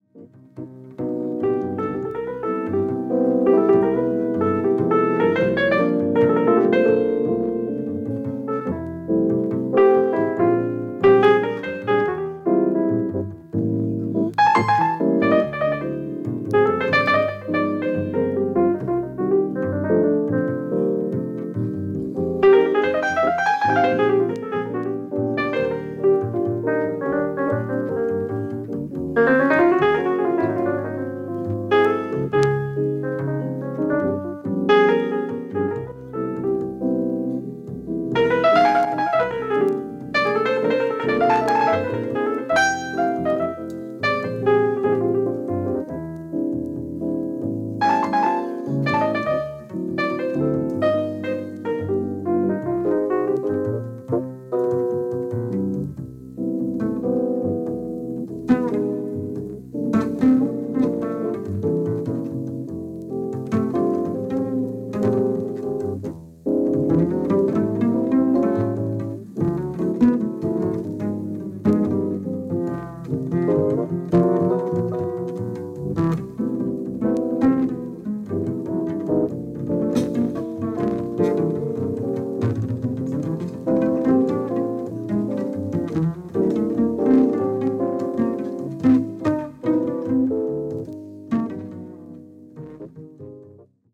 Bass
Piano